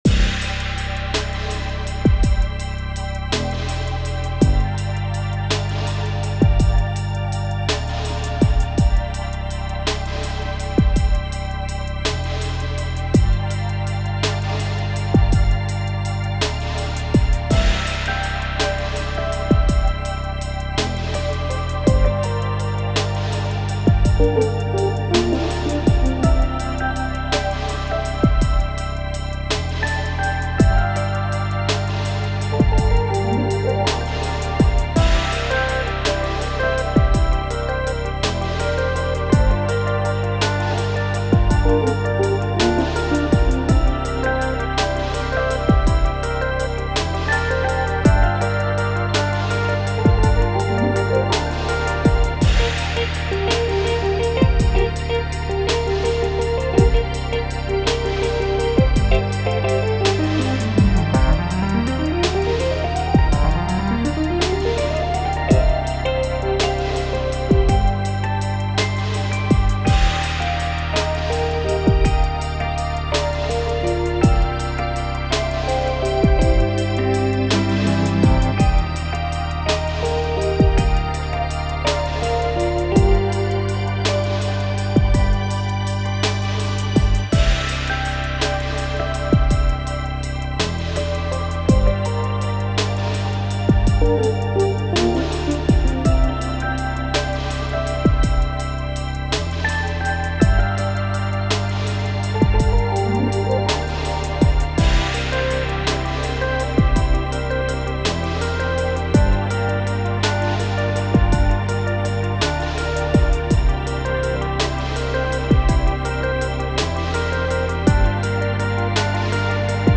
Sweet chimes and retro charm in the downtempo kitchen